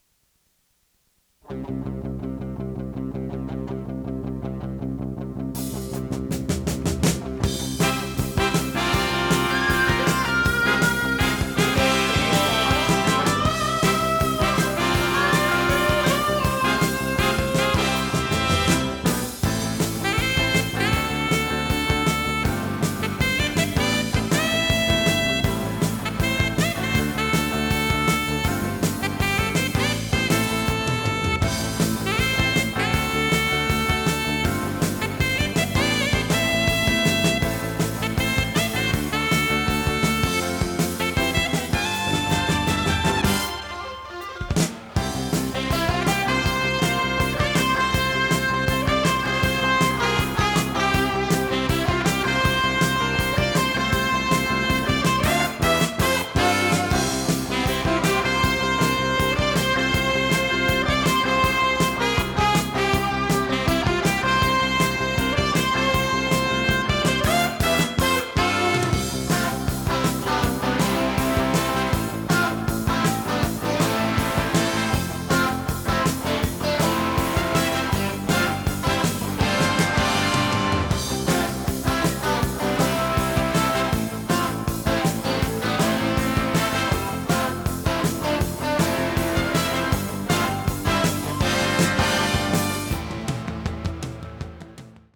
テープ：RTM
ノイズリダクションOFF
【フュージョン・ロック】96kHz-24bit 容量52.2MB